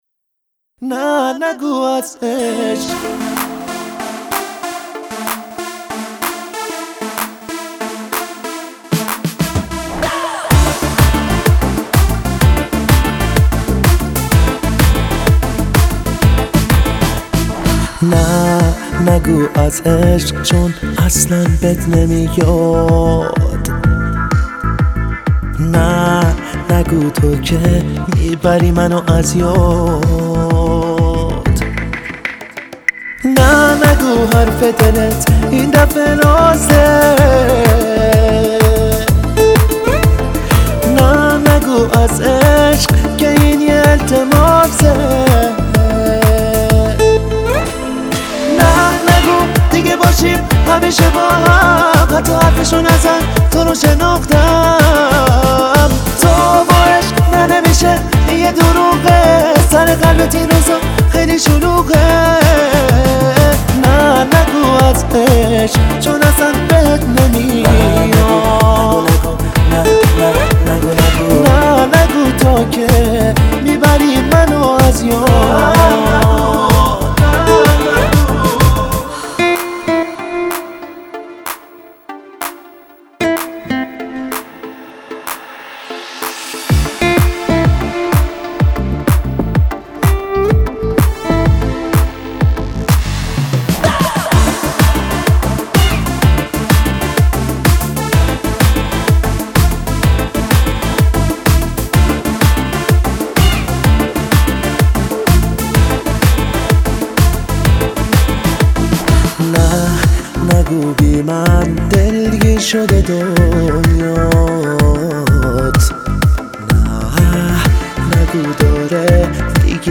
Happy Song